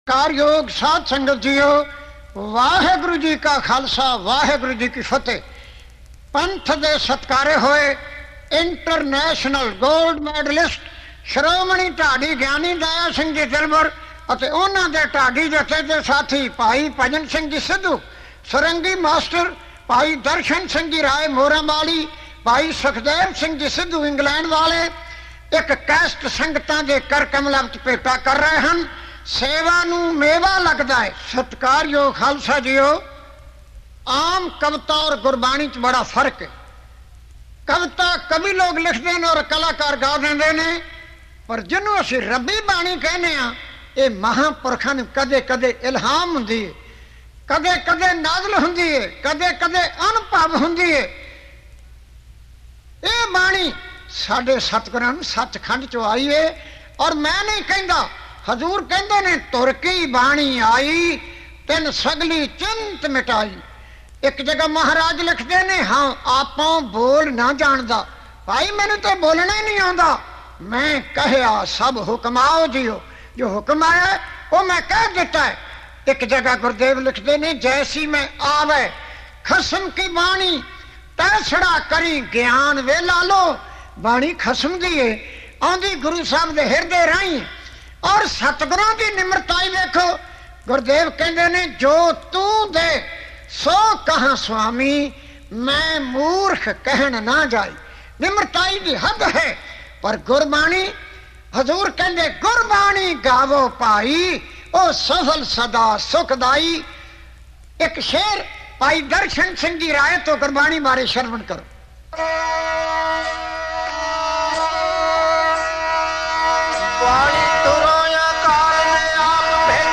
Genre: Dhadi Varan